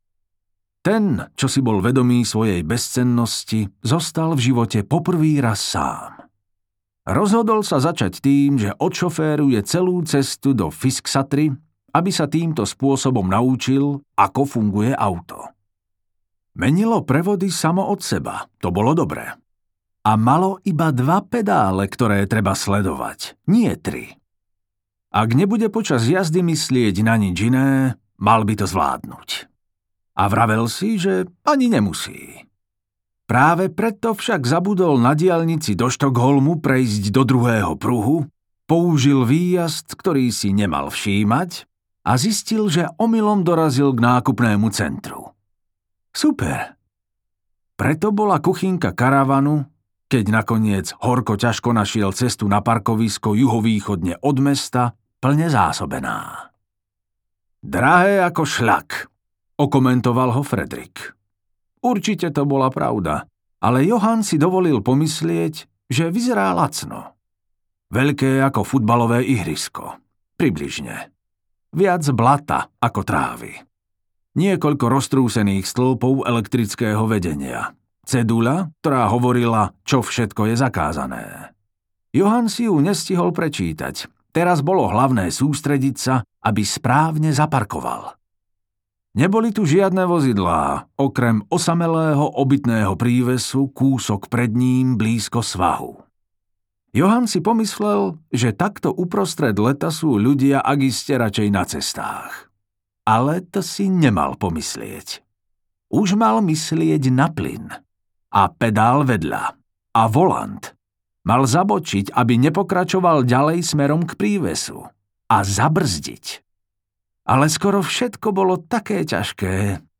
Prorokyňa a trkvas audiokniha
Ukázka z knihy